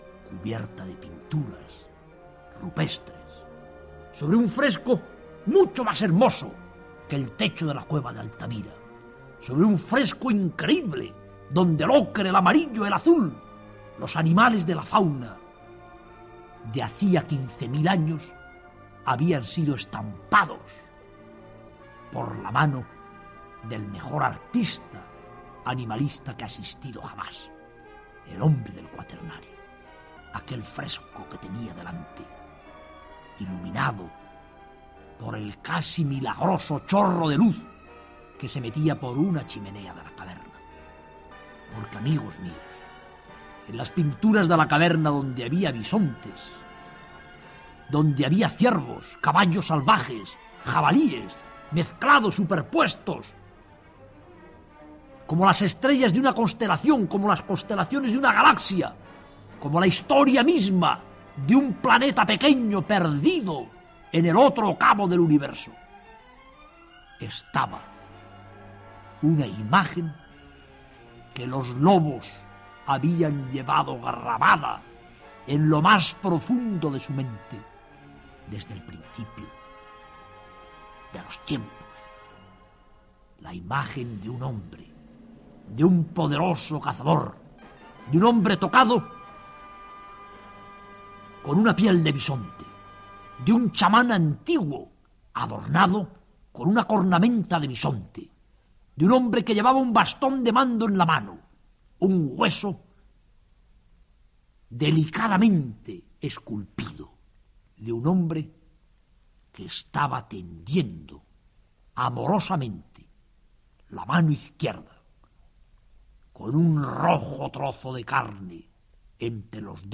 El Cuento de Lobos, que FRF grabó en 1976 en Radio Nacional de España, seis horas de relato oral nunca escrito, contiene una escena cumbre en la que la protagonista ve en una cueva una pintura rupestre que representa el pacto de la alianza entre los magdalenienses y el lobo (relación que acabaría tan mal para el cánido salvaje, al suplantar los neolíticos a los paleolíticos españoles y al lobo aliado convertirle en perro esclavo).
El estudio de su voz, emitida siempre sin leer, sin haber escrito y memorizado el guión previamente, puede que esconda y dónde se encuentre explicación a la capacidad de atracción que tuvo su obra audiovisual.